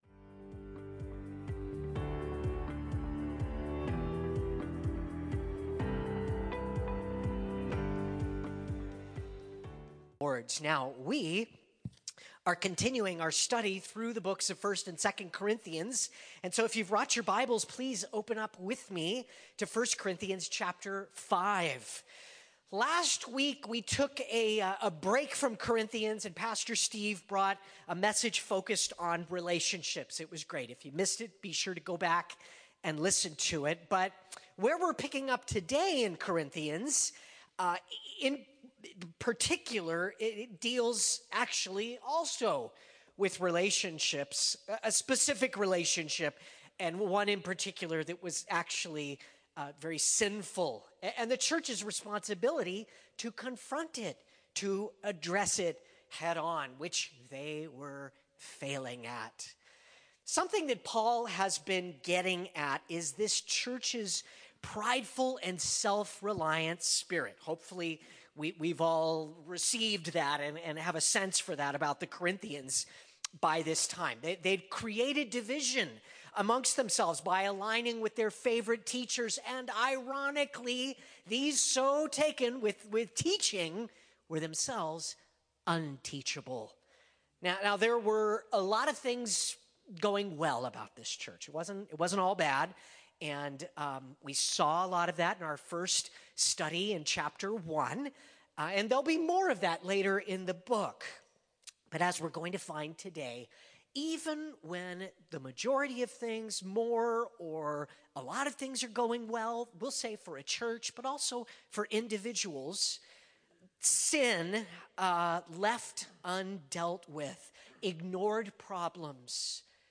… continue reading 25 एपिसोडस # Religion # Calvary # Chapel # Christianity # Sermons